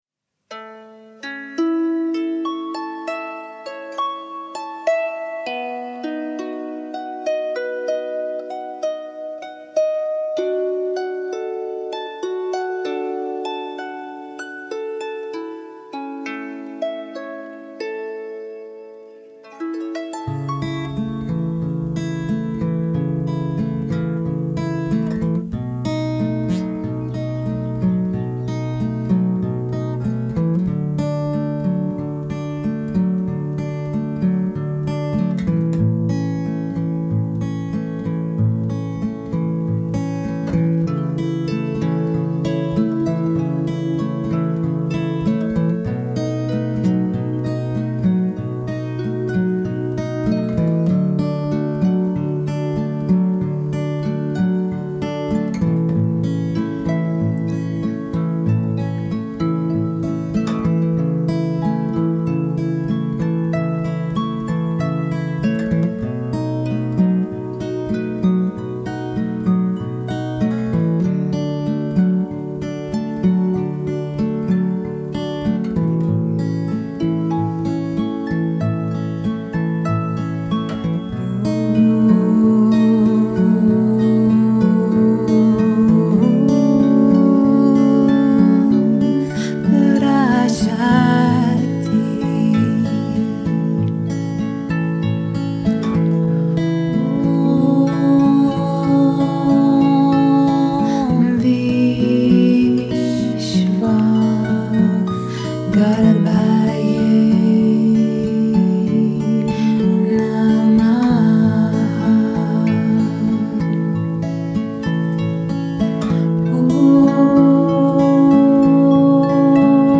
Harfe